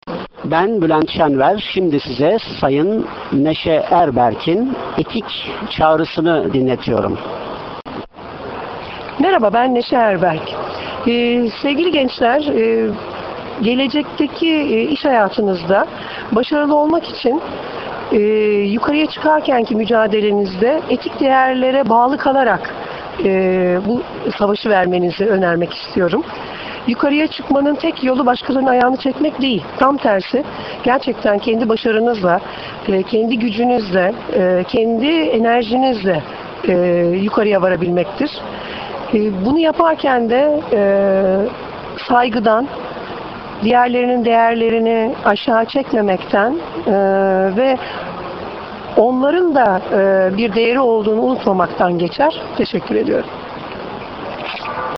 NEŞE ERBERK Etik Çağrısını kendi sesinden dinlemek için, bilgisayarınızın sesini açıp,